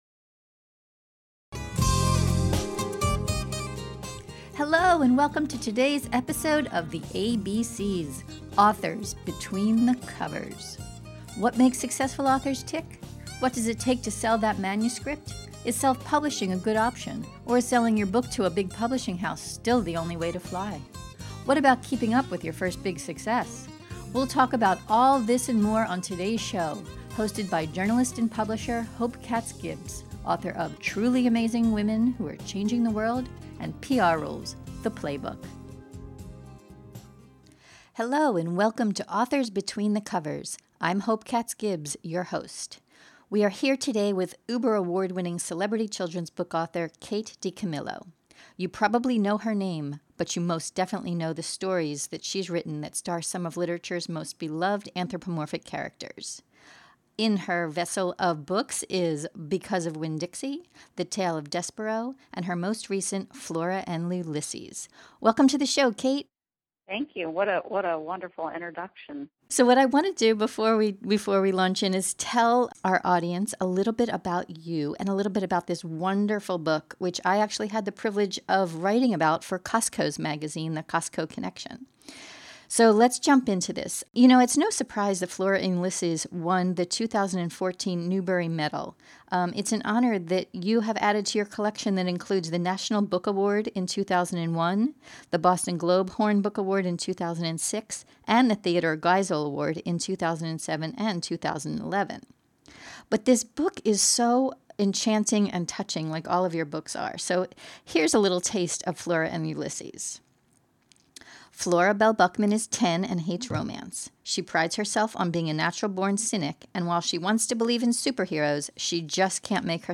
In this podcast interview, you’ll learn: DiCamillo’s process for writing her best-sellers and tips on how others aspire to her achievement level might get started.